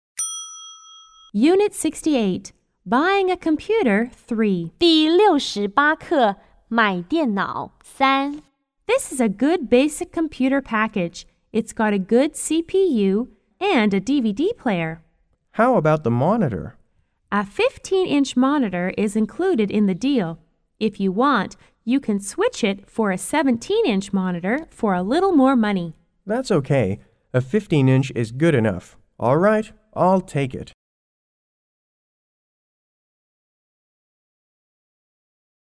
S= Salesperson C= Customer